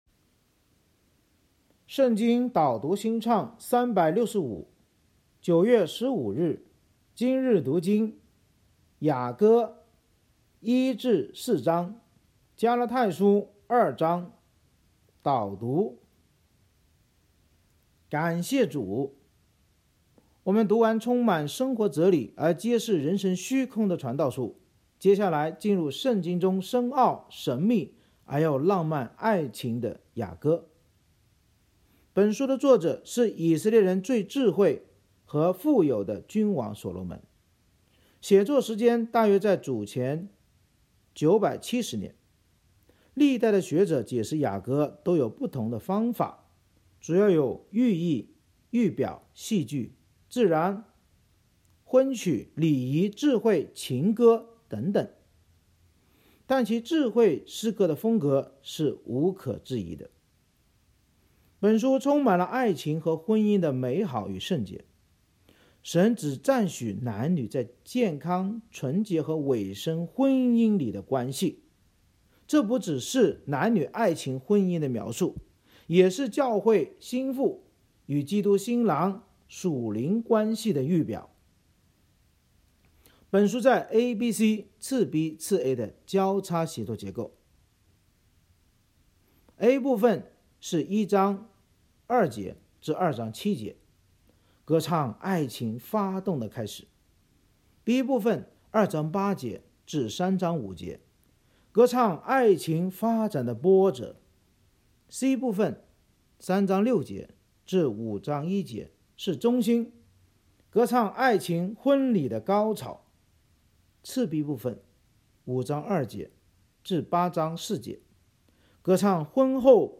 圣经导读&经文朗读 – 09月15日（音频+文字+新歌）